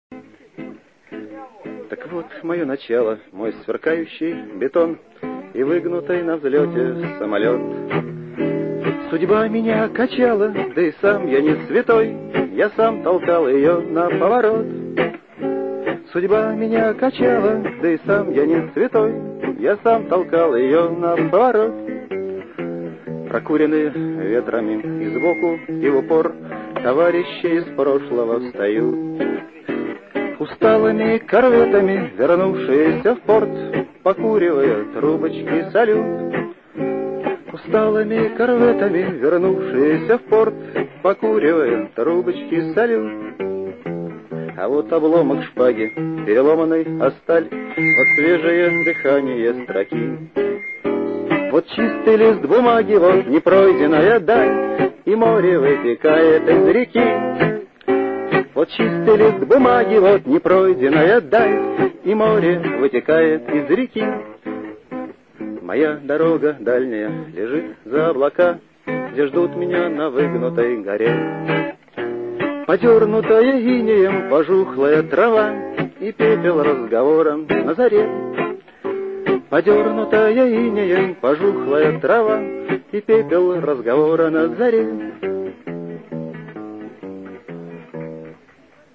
Отшумел первый день регаты, гаснет закат над лагерем на Парусном берегу.